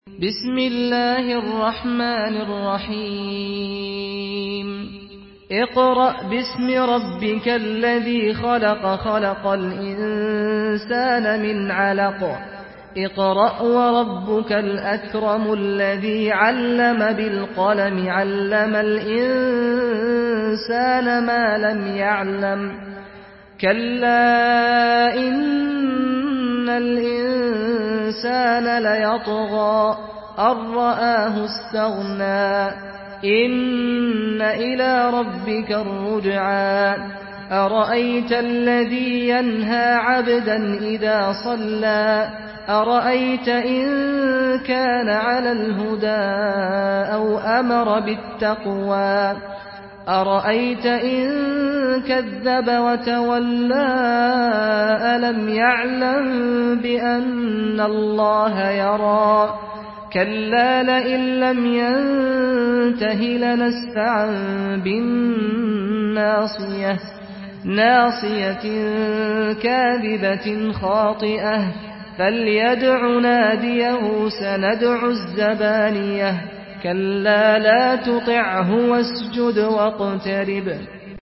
سورة العلق MP3 بصوت سعد الغامدي برواية حفص
مرتل